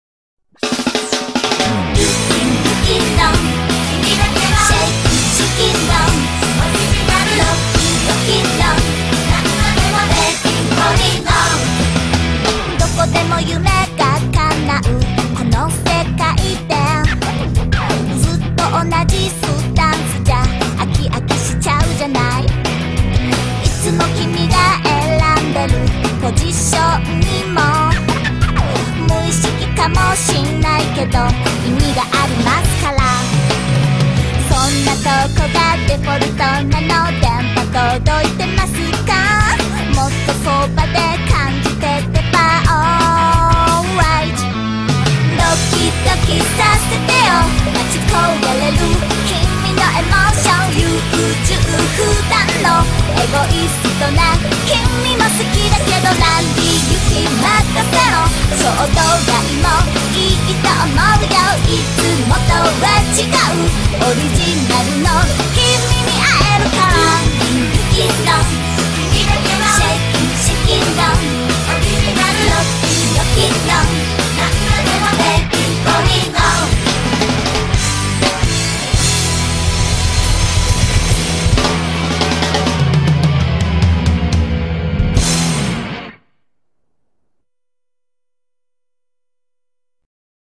BPM177
Audio QualityCut From Video